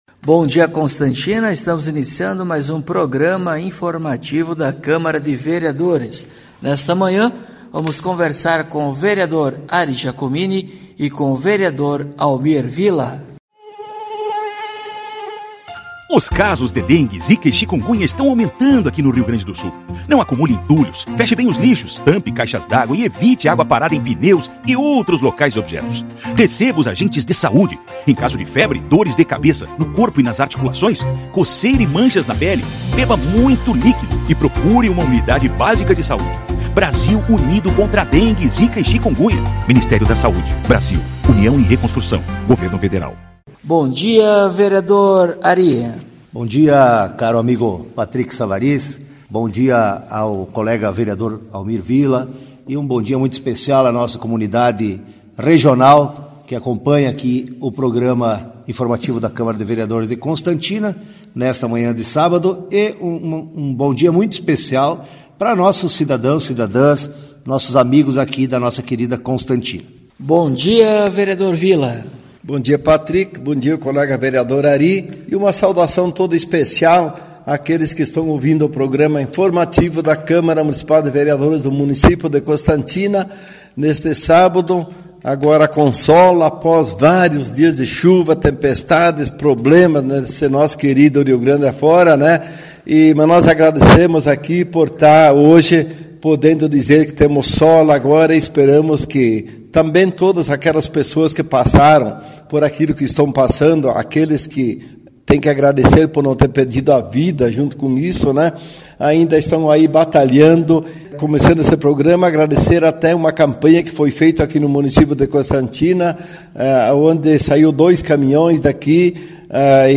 Acompanhe o programa informativo da câmara de vereadores de Constantina com o Vereador Ari Giacomini e o Vereador Almir Villa.